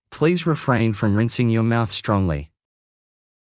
ﾌﾟﾘｰｽﾞ ﾘﾌﾚｲﾝ ﾌﾛﾑ ﾘﾝｼﾝｸﾞ ﾕｱ ﾏｳｽ ｽﾄﾛﾝｸﾞﾘｰ